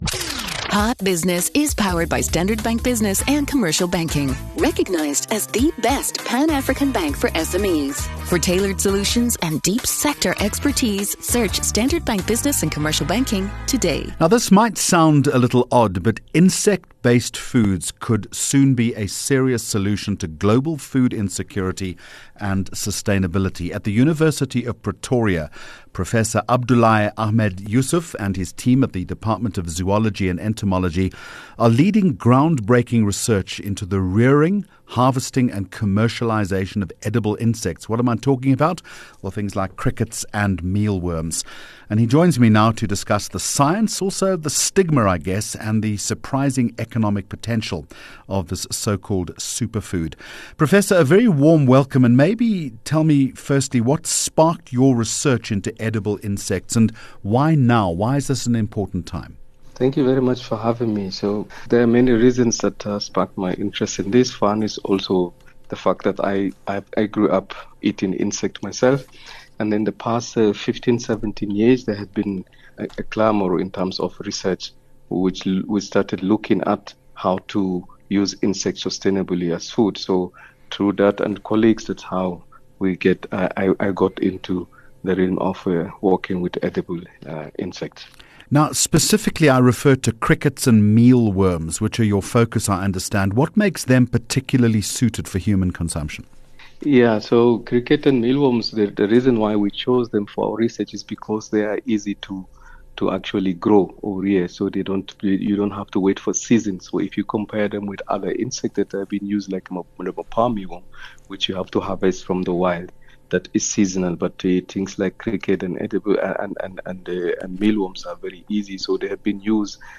25 Jun Hot Business Interview